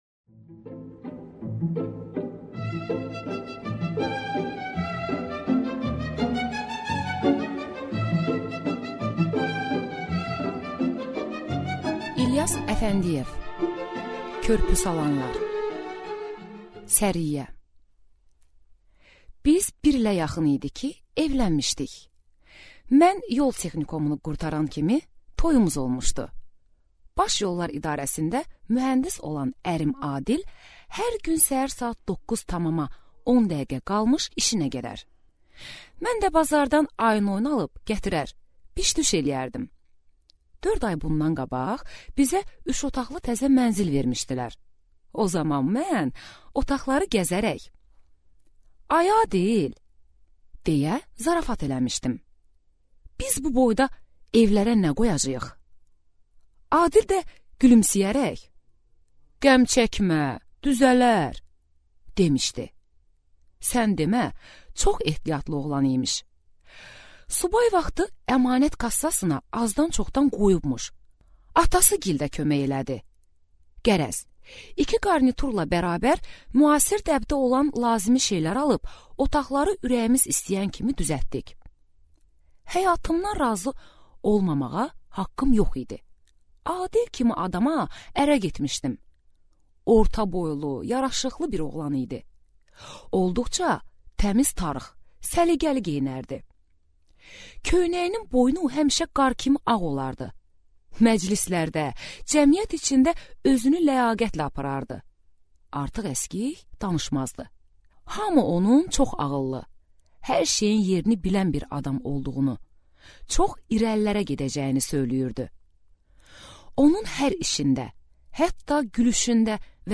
Аудиокнига Körpüsalanlar | Библиотека аудиокниг